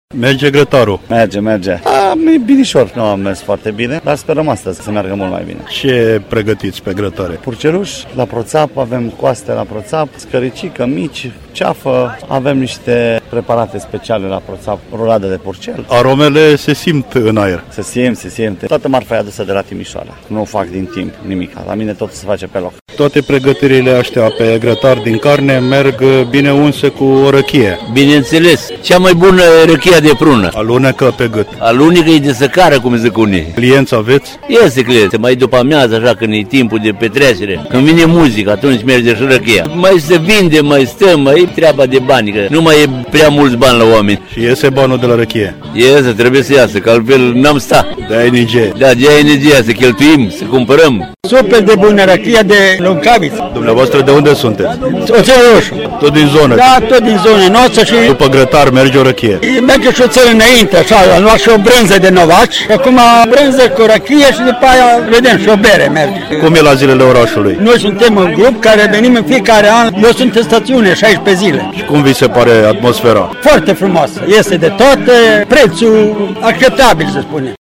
În Parcul Central al stațiunii de pe au continuat astăzi manifestările din cadrul ZILELOR ORAȘULUI BĂILE HERCULANE, care adună în Centrul Istoric al stațiunii un mare număr de localnici, turiști, dar și foarte mulți participanți din zonele apropiate!